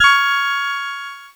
Cheese Chord 19-C#4.wav